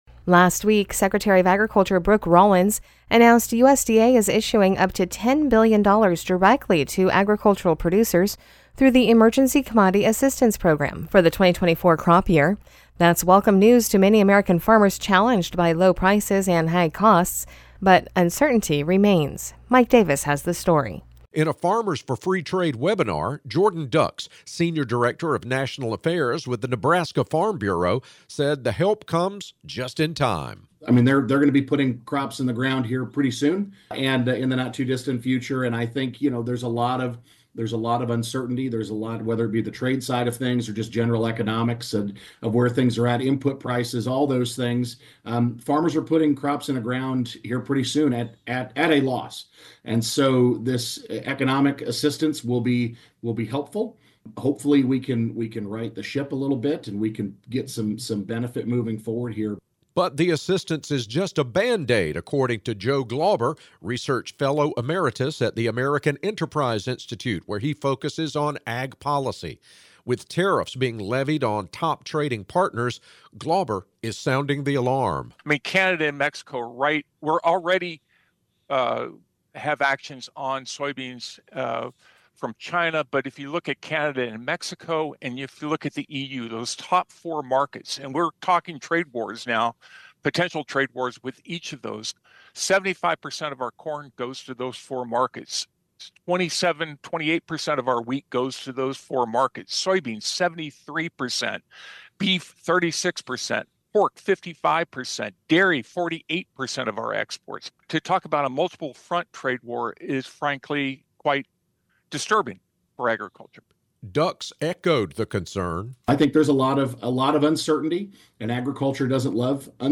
Synopsis: This audio report is a conversation between a reporter and ag secretary Brooke Rollins. She promises up to $10 billion for Emergency Commodity Assistance to help make up for lost sales.